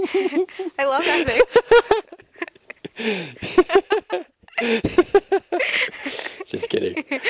Examples with Downsteps